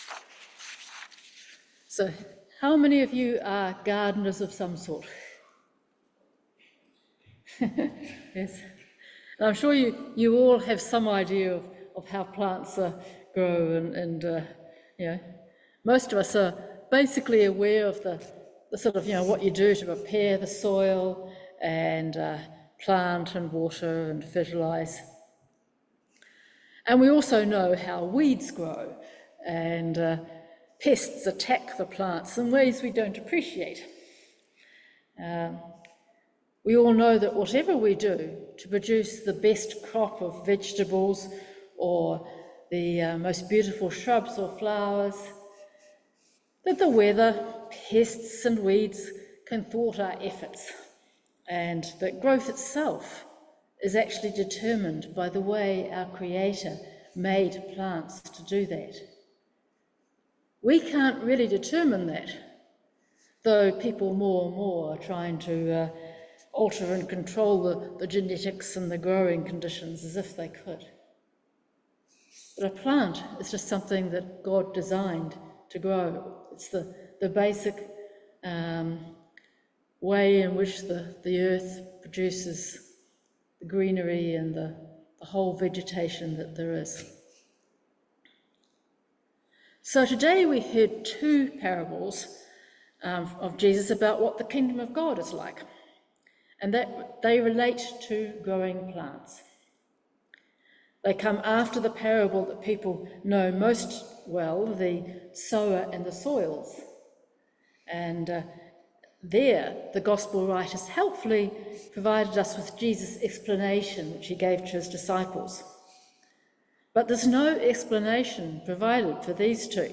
Service Type: Morning Worship
Sermon-16-June-.mp3